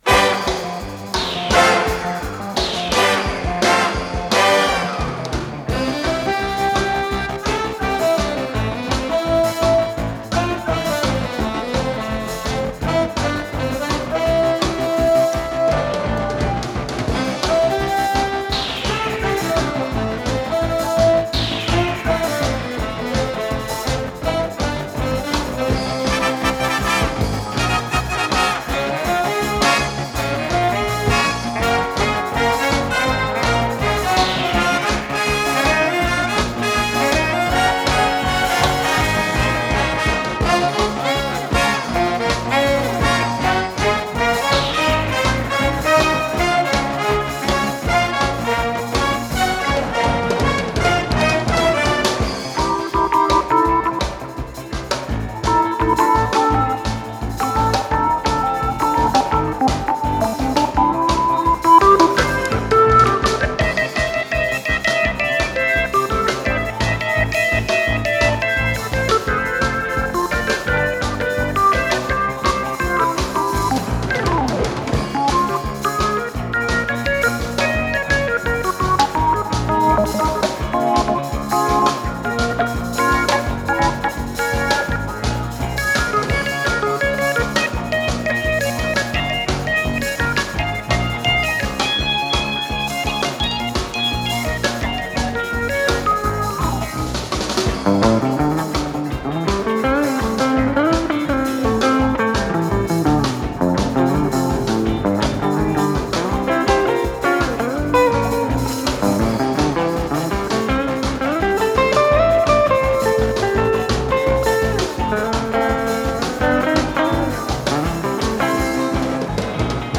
До мажор - соль мажор
Скорость ленты38 см/с
ВариантДубль моно